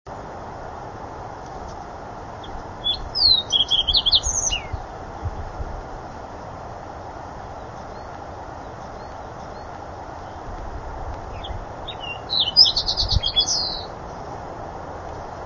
Song Sparrow
Notice the long pauses between song sequences. This bird sang in virtual isolation aside from the sound of the running mountain stream far below. wave809
sparrow_fox_809.wav